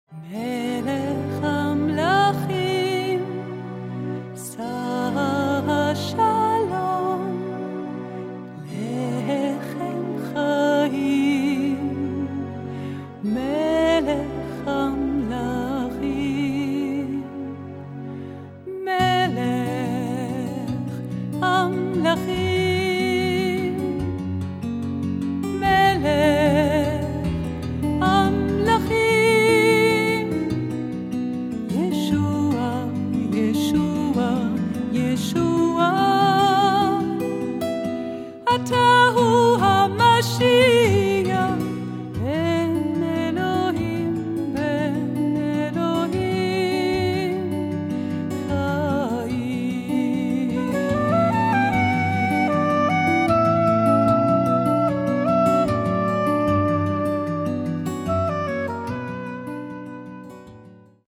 Psalmen, Gospel, Poesie & Jazz (hebräisch/deutsch)
daneben treten besonders Saxophon und Querflöte hervor.